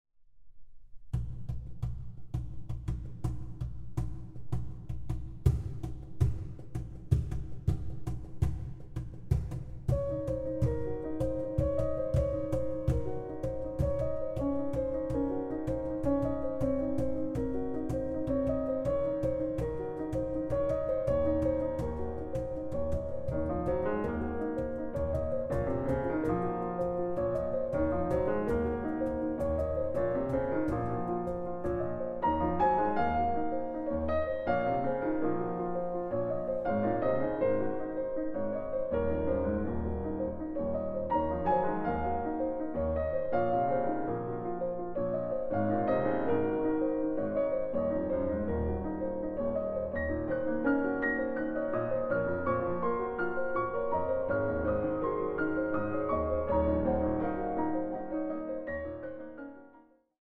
Recording: Mendelssohn-Saal, Gewandhaus Leipzig, 2024